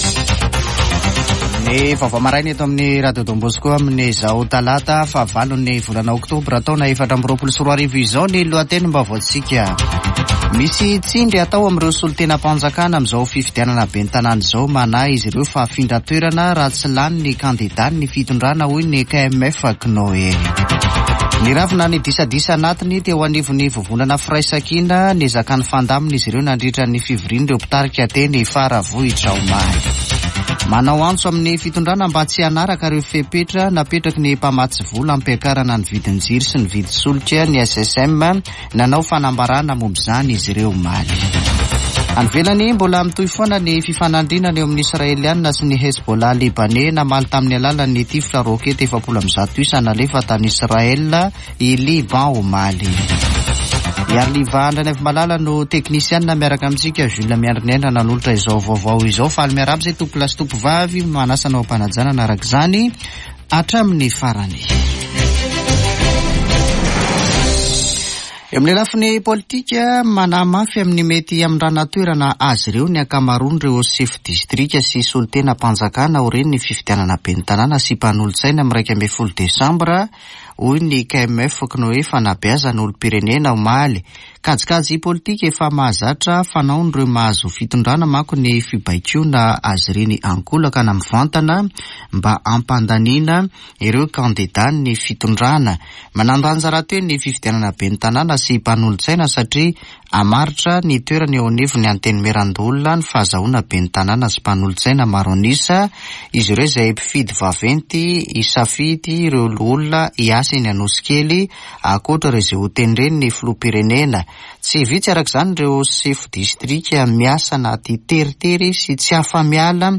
[Vaovao maraina] Talata 8 oktobra 2024